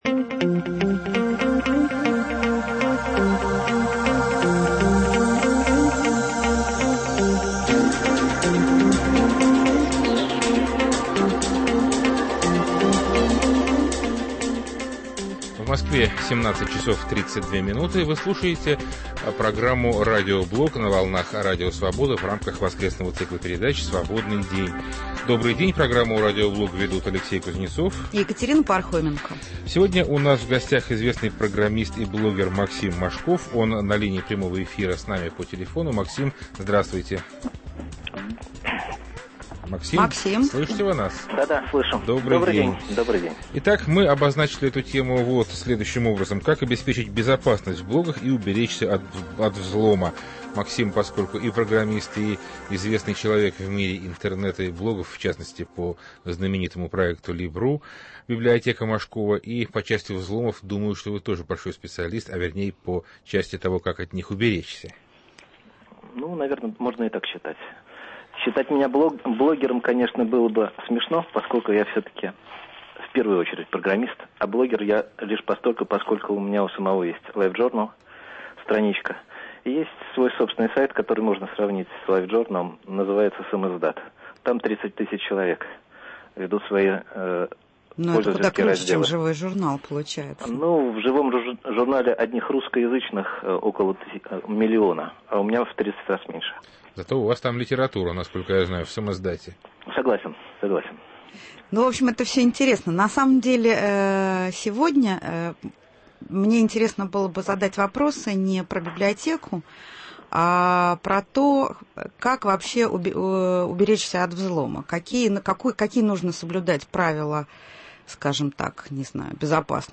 Как обеспечить безопасность в блогах и уберечься от взлома? Гость программы - известный программист и блоггер Максим Мошков.